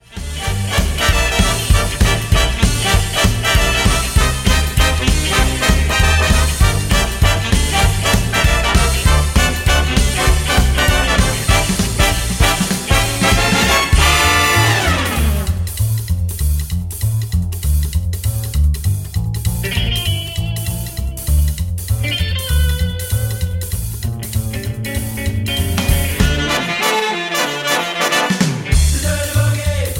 Backing track files: 1990s (2737)